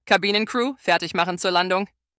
CrewSeatsLanding.ogg